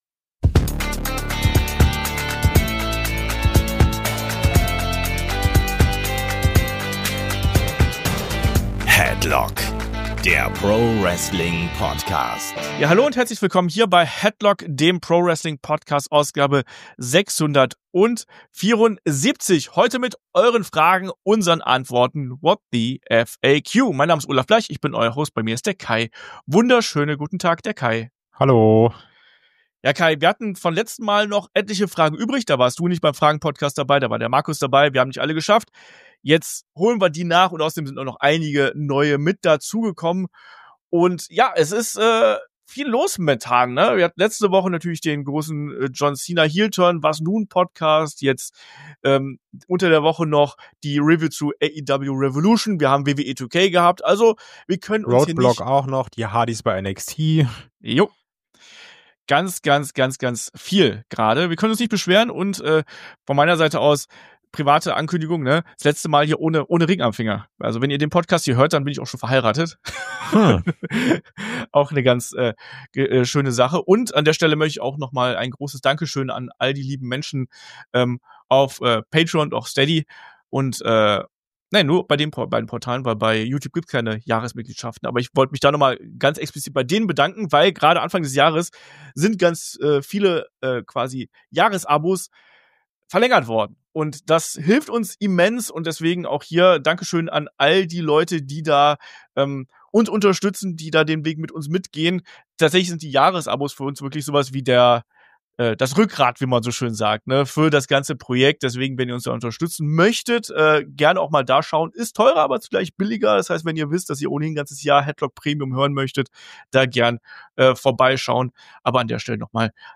Headlock ist die Wrestling-Gesprächsrunde: Hier plaudert man nicht nur über das aktuelle WWE-Geschehen, sondern wirft auch einen Blick über den Tellerrand.